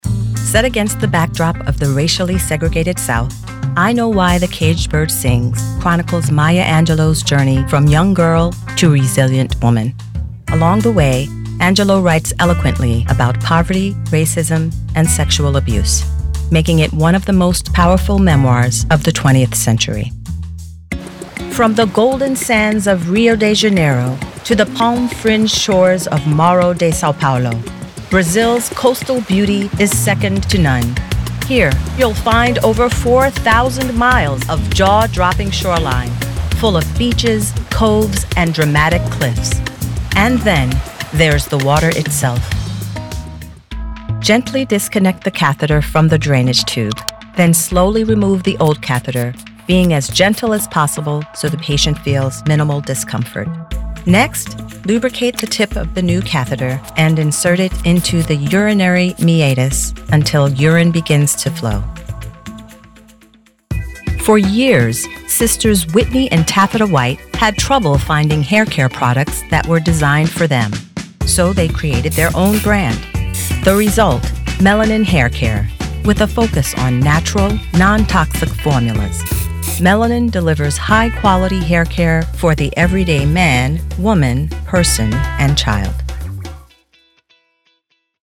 Narration Demo
Neutral American accent with a warm, grounded delivery.
Middle Aged